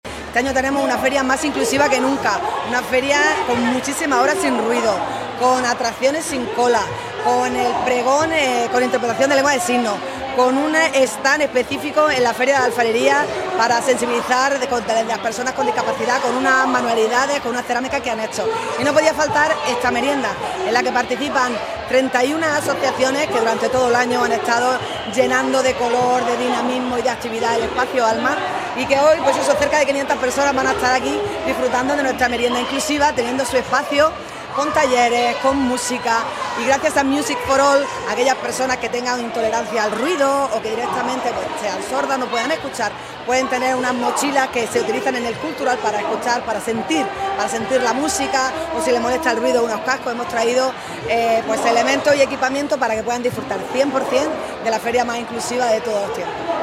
La alcaldesa, María del Mar Vázquez, asiste a ‘La Feria a tu ritmo’ y asegura que “el Ayuntamiento trabaja para hacer de Almería una ciudad cada día más saludable e inclusiva”
CORTE-ALCALDESA-FERIA-A-TU-RITMO.mp3